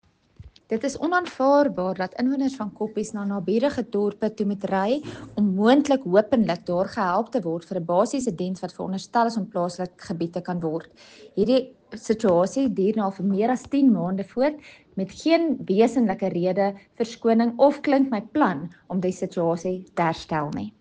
Afrikaans soundbites by Cllr Carina Serfontein and Sesotho soundbite by Jafta Mokoena MPL